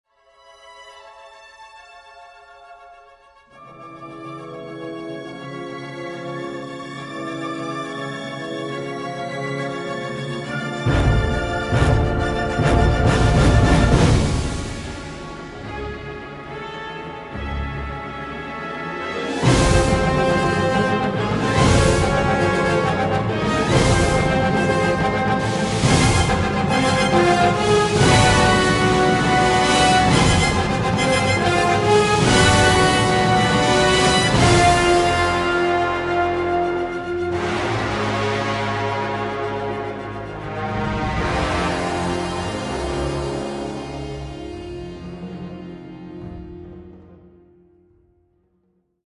The battling brass and pounding drums